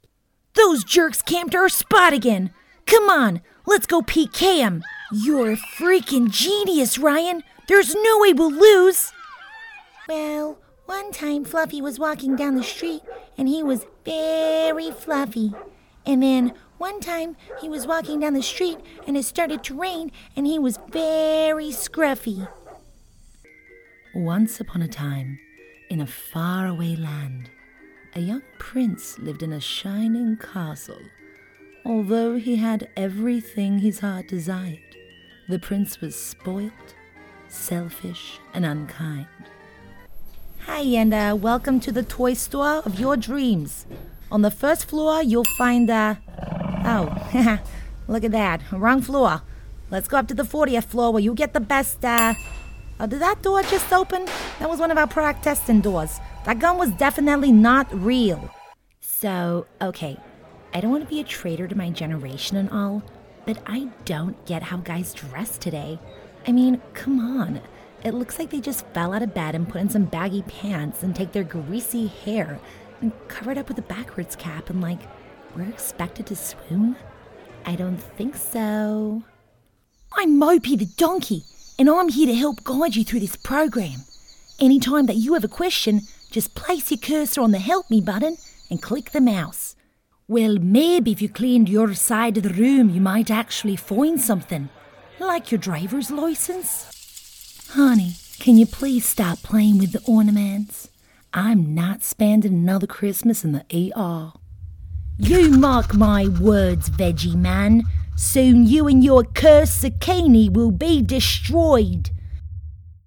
Female
Character / Cartoon
Character/Animation/Cartoons